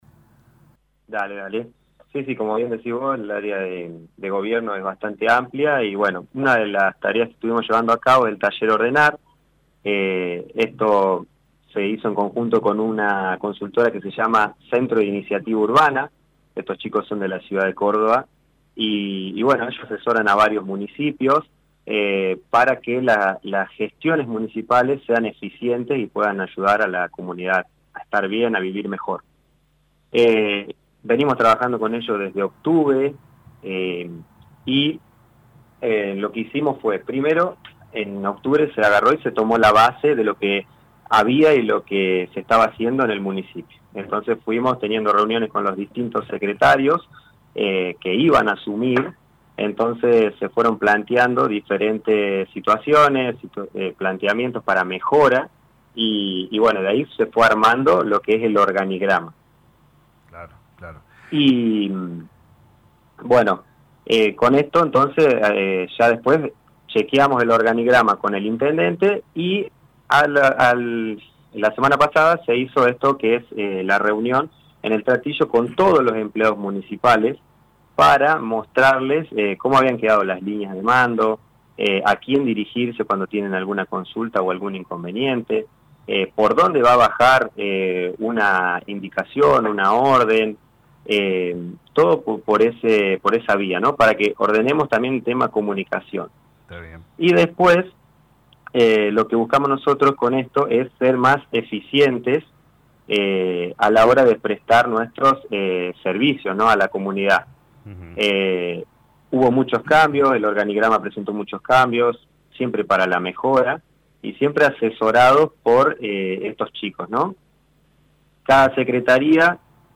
El Secretario de Gobierno Municipal Marcos Grande habló en LA MAÑANA DE LA RADIO sobre las distintas reuniones, talleres y capacitaciones que se vienen realizando en el marco del Plan de Modernización Municipal.